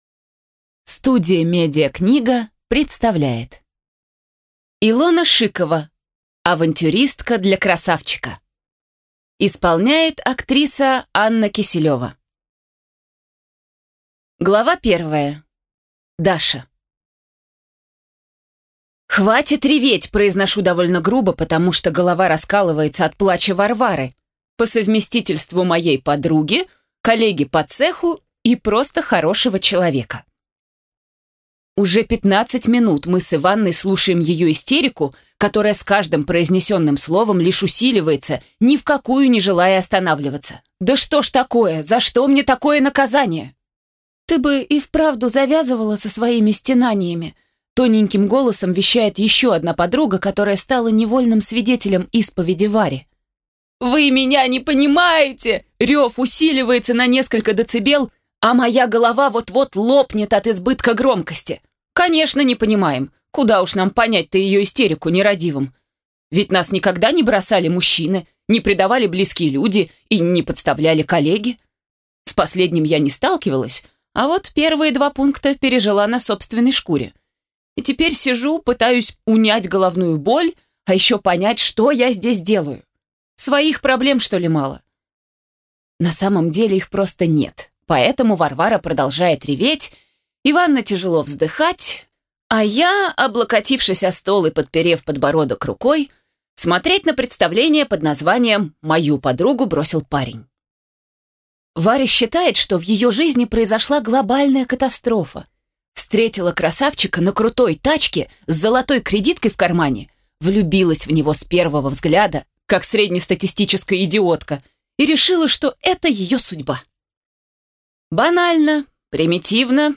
Аудиокнига Авантюристка для красавчика | Библиотека аудиокниг